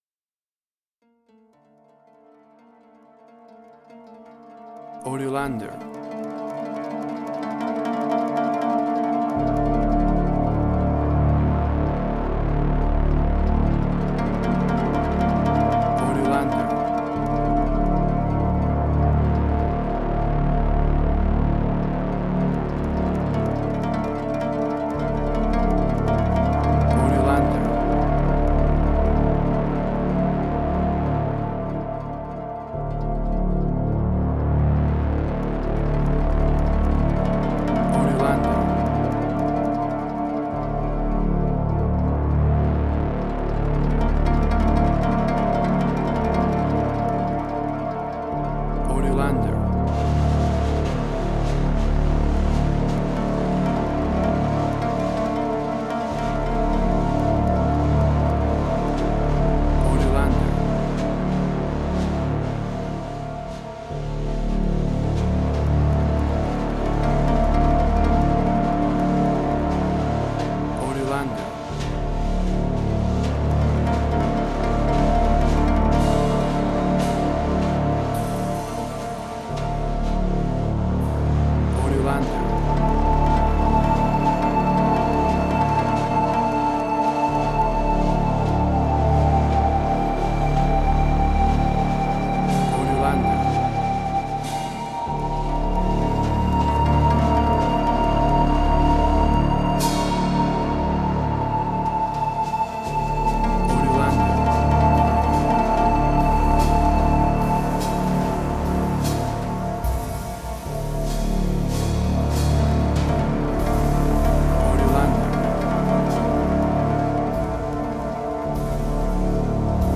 Suspense, Drama, Quirky, Emotional.
WAV Sample Rate: 16-Bit stereo, 44.1 kHz
Tempo (BPM): 77